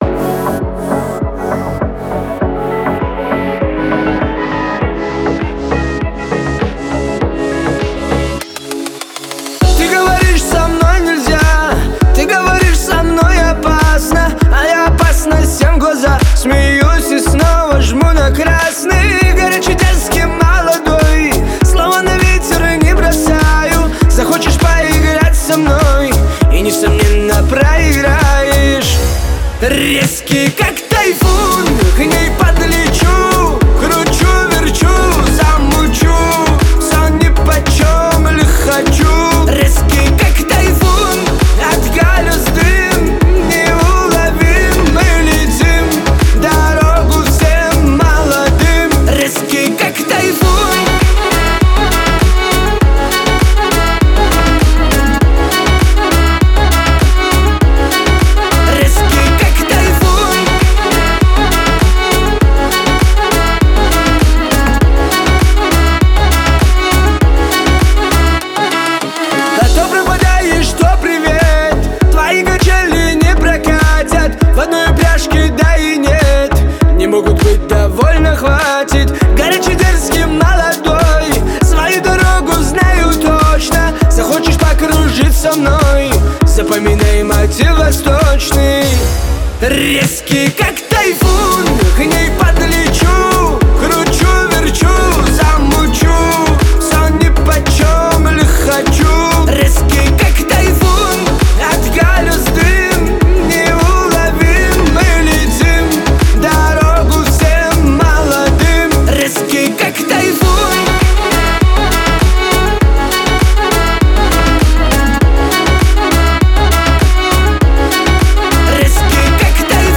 танцевальные песни